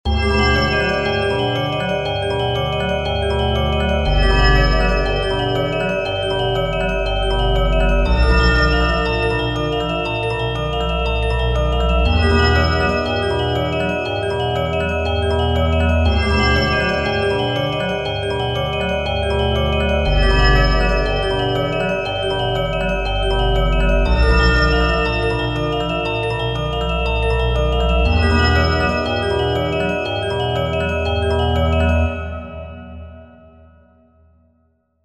Short 120bpm loop in 22edo
22edo_demo.mp3